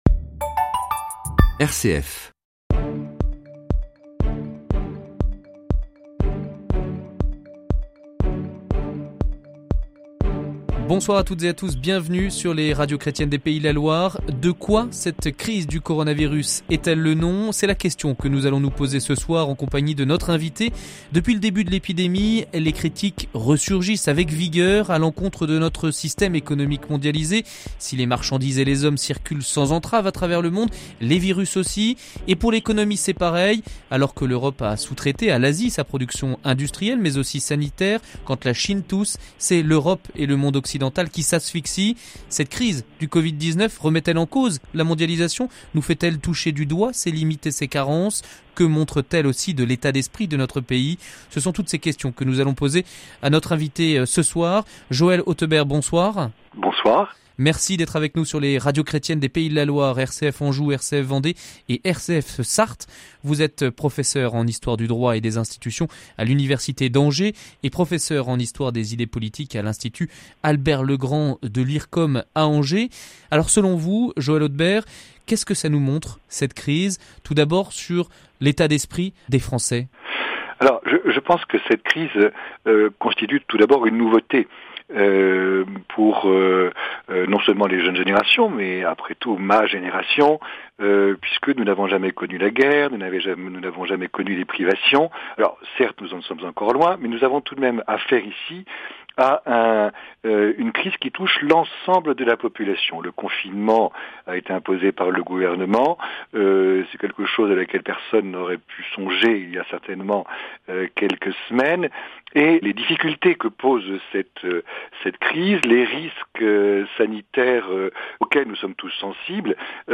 Pendant ce temps inédit de confinement, les relations au sein d’une famille avec enfants peuvent devenir compliquées. Radio Fidelité à interviewé